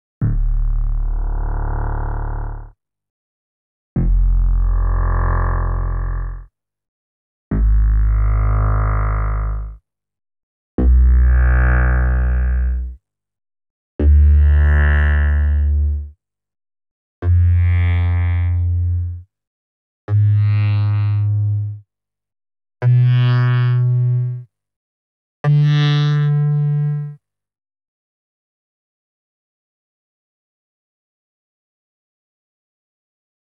09_MergeSynth_D+3_1-3.wav